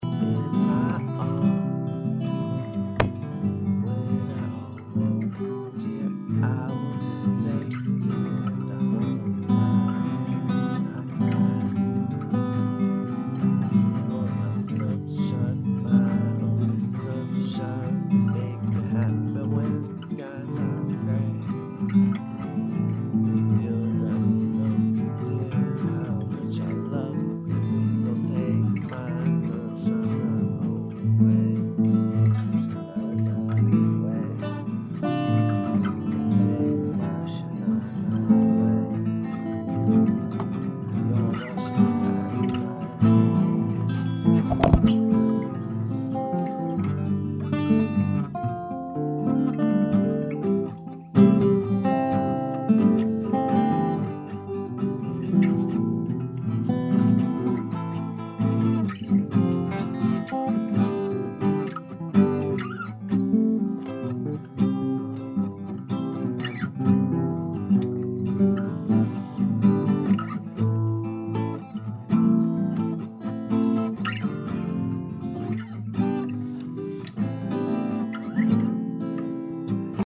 Audio Montage